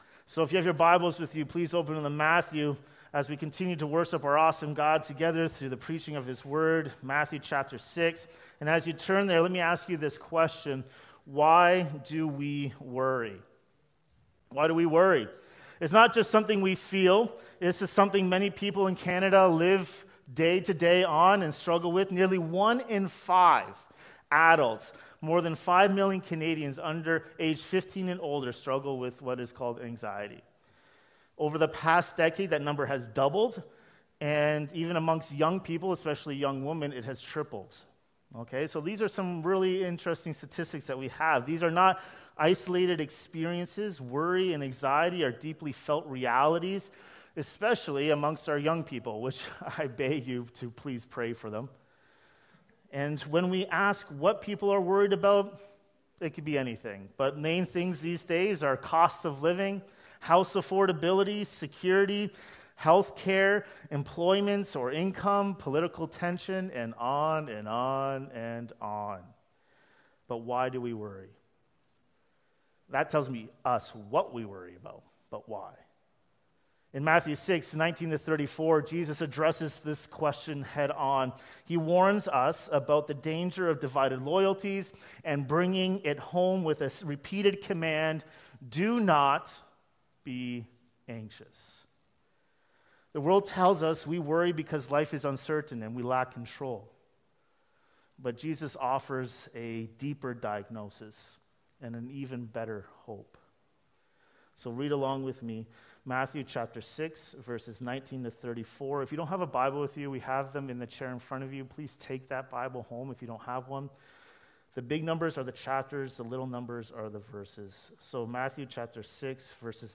Treasures, Trust, and Worry | A Sermon on Matthew 6:19–34